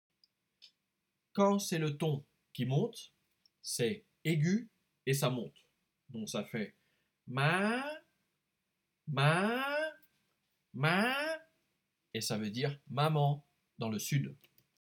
Cours sur la prononciation
Dấu sắc